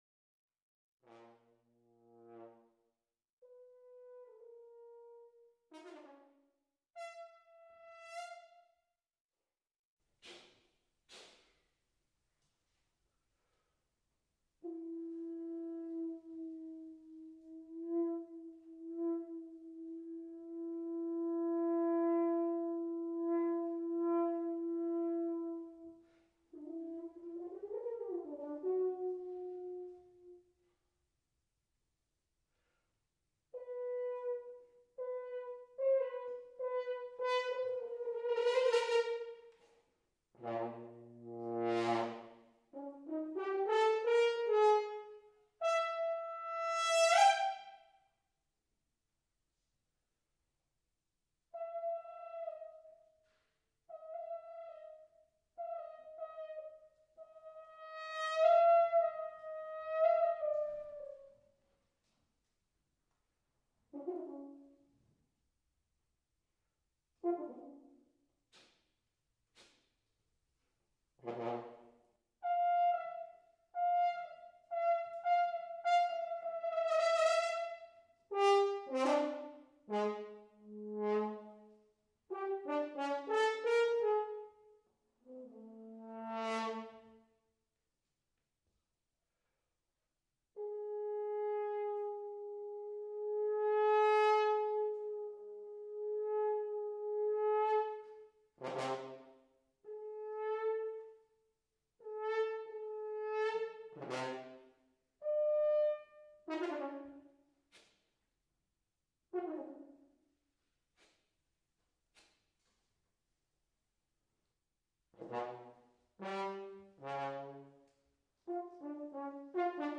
für Horn solo